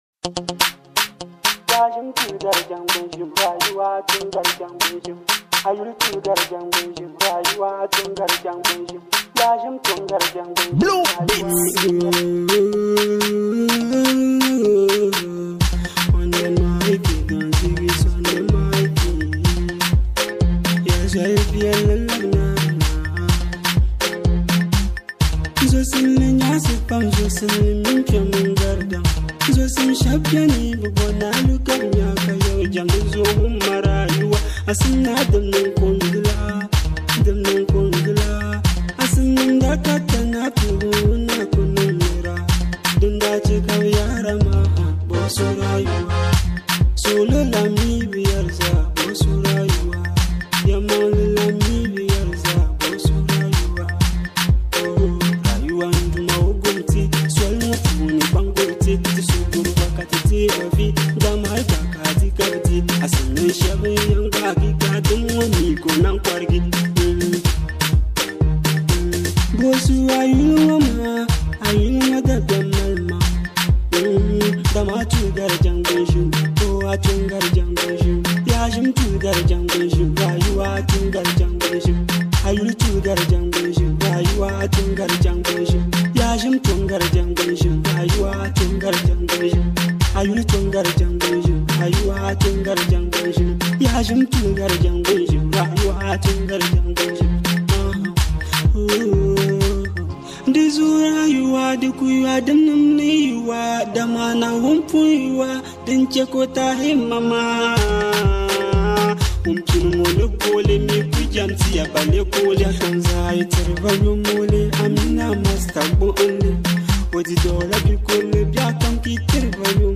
With its smooth flow and emotional depth